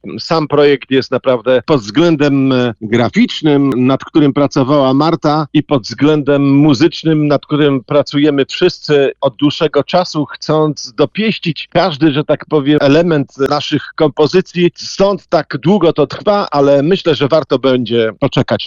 Na antenie Radia Deon Chicago lider grupy, kompozytor, instrumentalista i wokalista – Grzegorz Stróżniak, oraz Marta Cugier – wokalistka pisząca teksty oraz menager, zapowiadają swoje przybycie do Wietrznego Miasta.